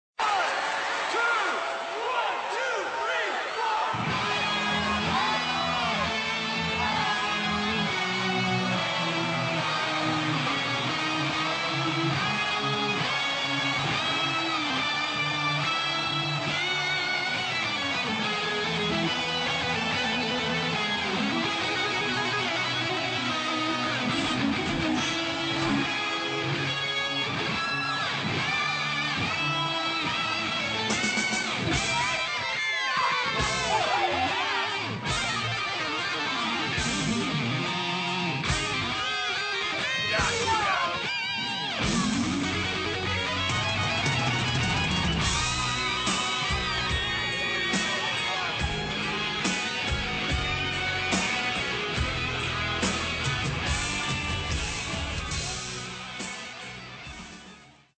as performed in the movie